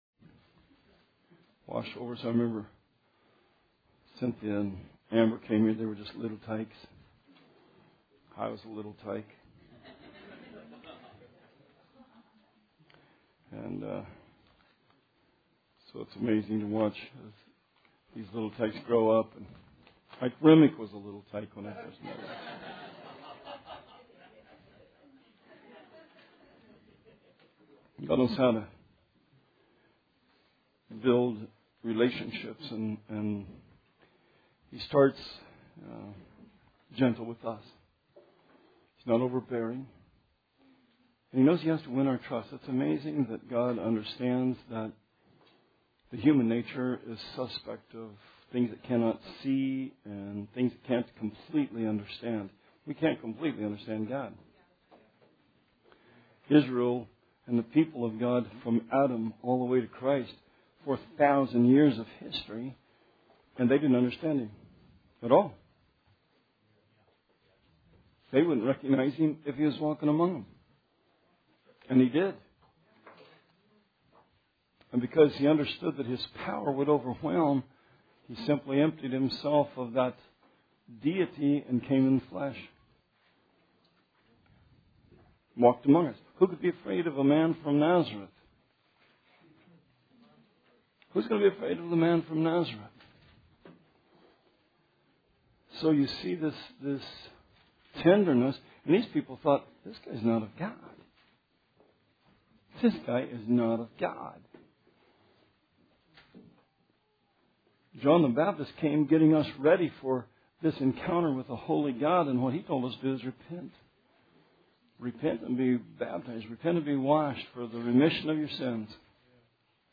Sermon 2/7/16